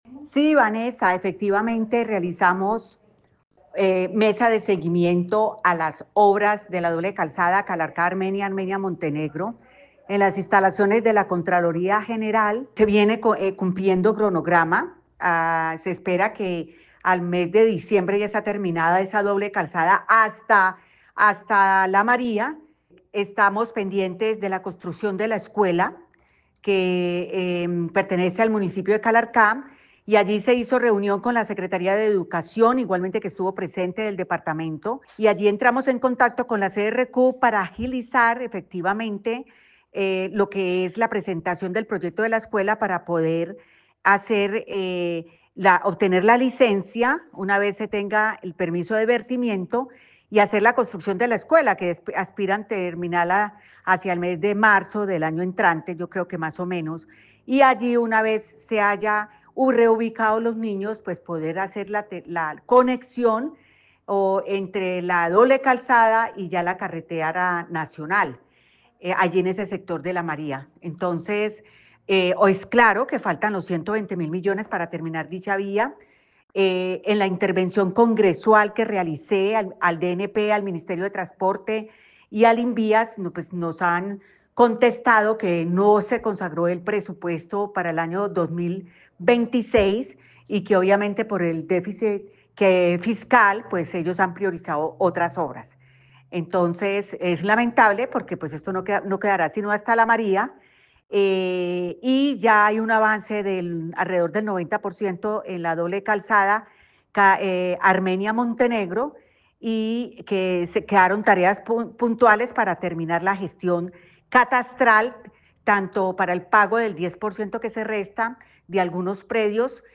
Representante a la cámara, Piedad Correal